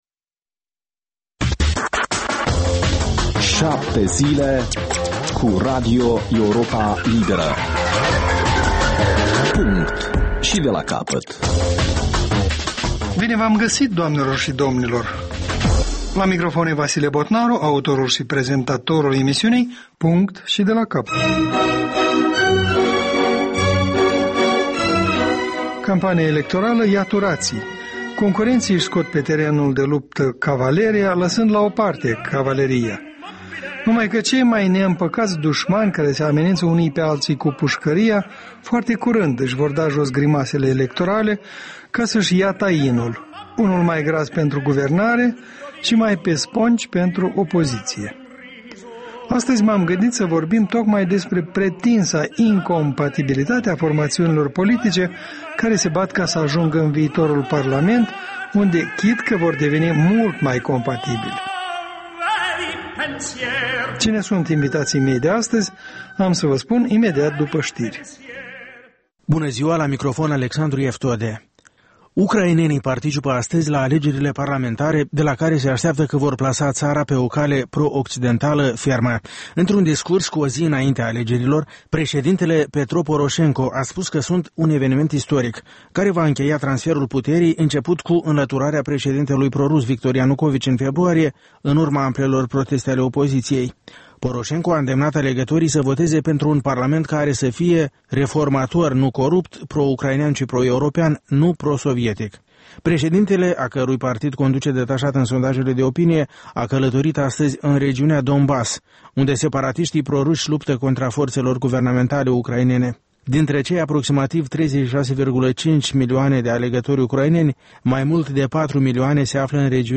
O discuţie la masa rotundă,